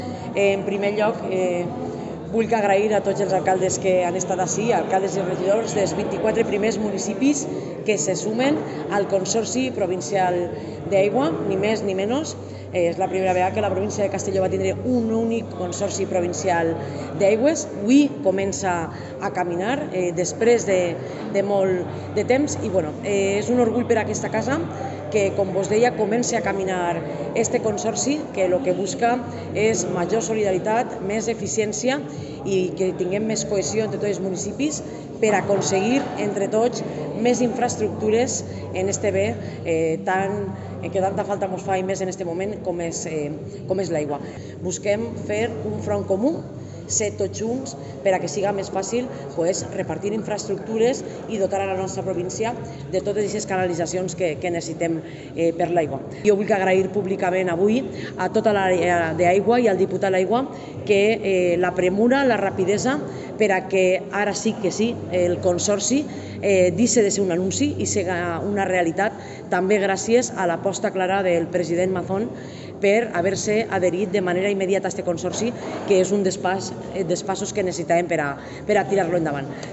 Presidenta Marta Barrachina